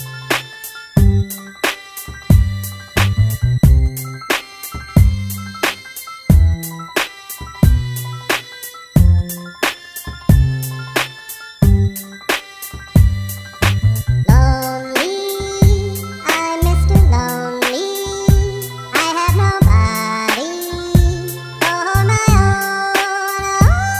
With No Backing Vocals At All R'n'B / Hip Hop 3:56 Buy £1.50